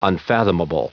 Prononciation du mot unfathomable en anglais (fichier audio)
Prononciation du mot : unfathomable